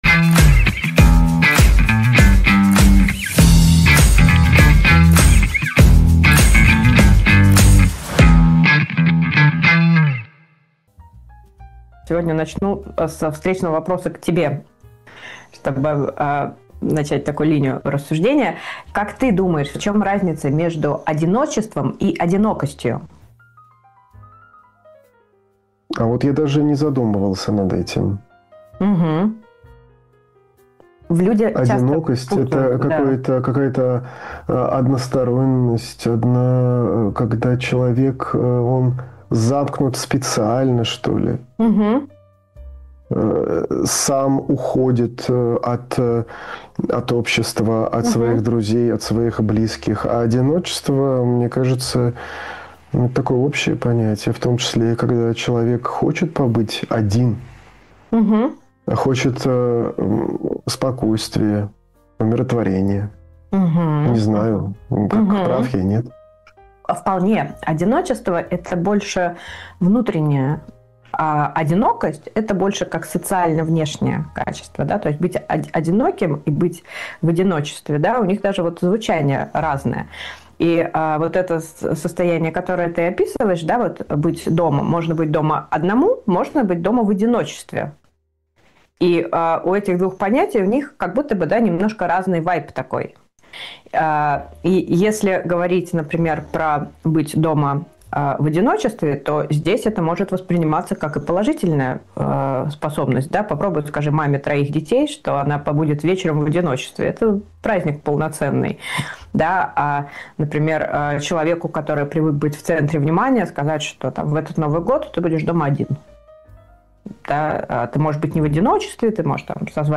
психолог